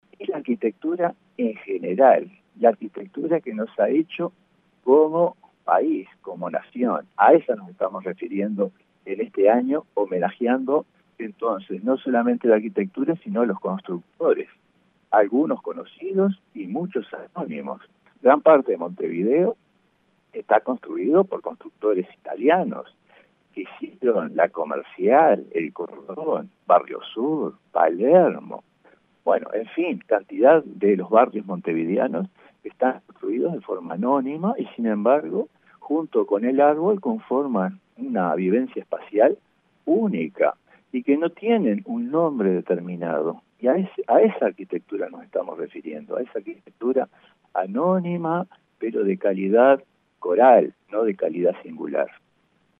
En diálogo con el Espectador, el Presidente de la Comisión de Patrimonio, Nelson Inda, adelantó que se trata de "un reconocimiento, no sólo a la arquitectura monumental y conocida, sino también a la que han hecho los uruguayos hace cientos de años y que han construido esta nación".